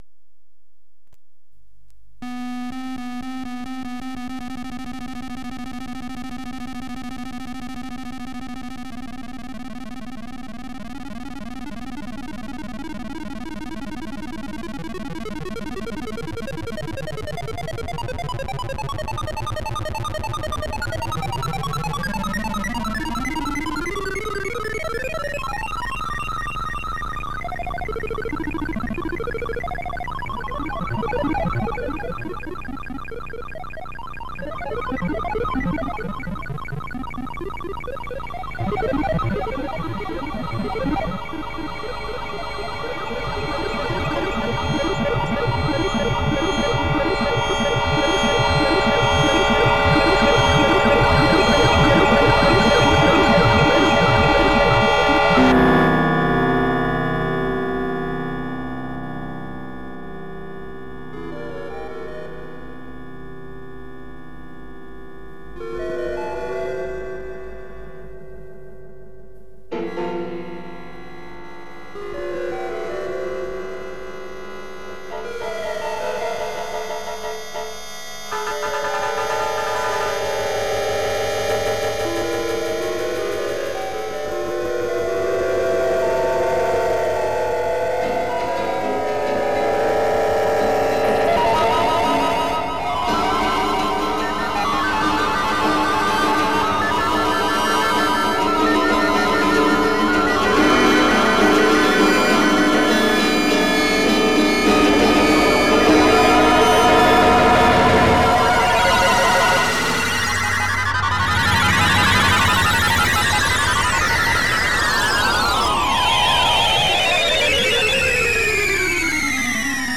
"RICERCARE",  composizione metafonica per computer, pianoforte, chitarra, e 14 oscillatori elettronici.